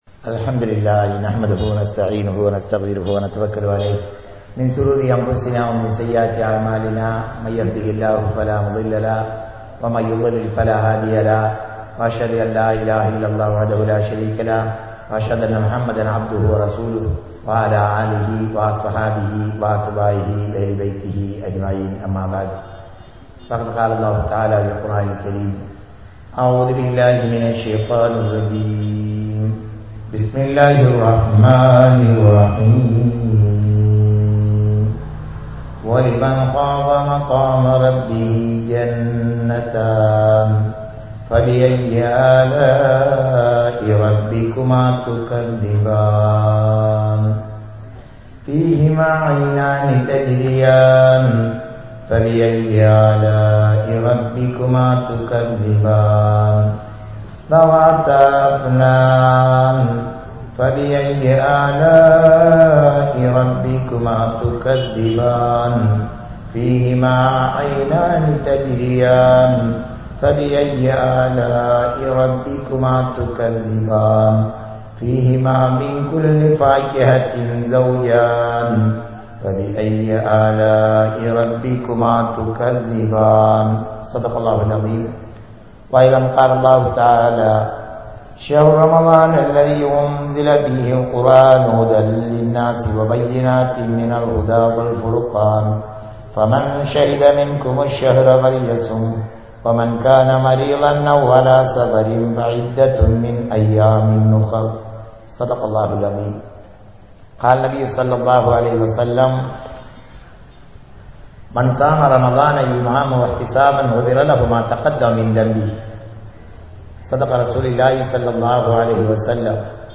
Allah`vin Nihmath | Audio Bayans | All Ceylon Muslim Youth Community | Addalaichenai